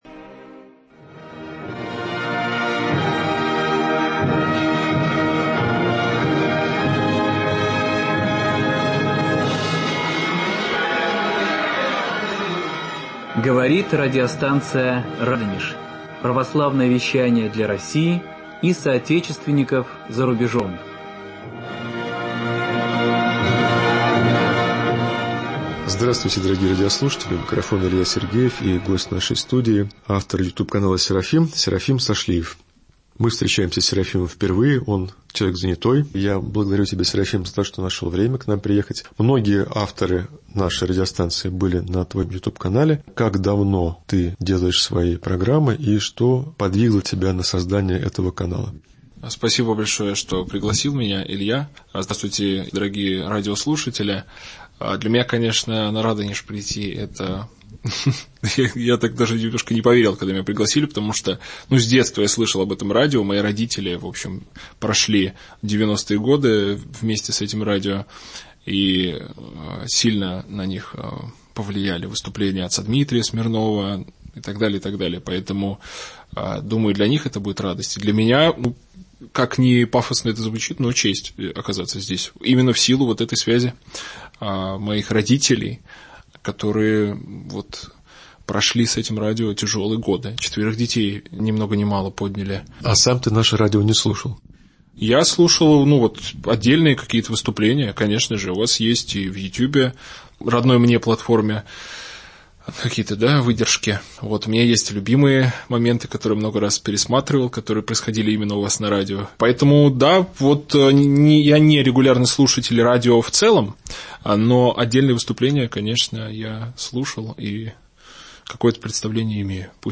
Беседа с автором ютюб-канала